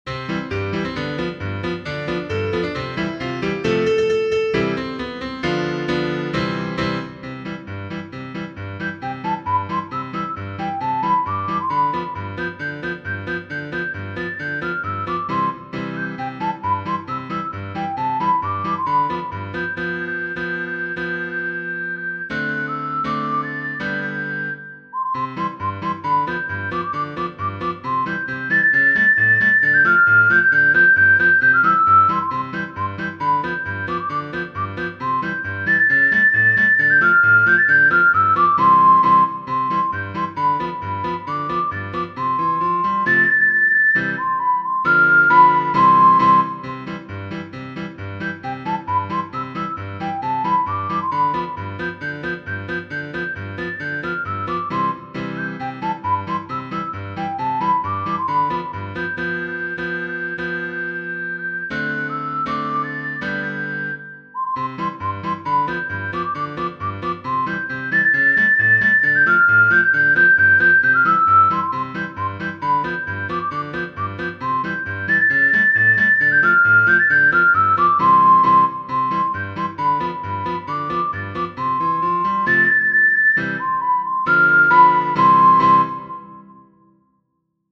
Gambardella, S. Genere: Napoletane Testo di Giovanni Capurro Mo nun só' cchiù Cuncetta, ma só' Lilí Kangy, sciantosa prediletta, avite voglia 'e dí!